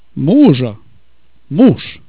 TABLE 3: Word End Voicing